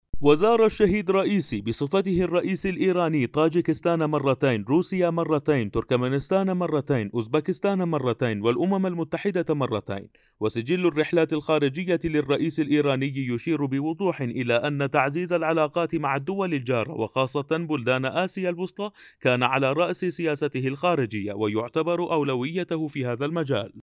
Male
Adult
informative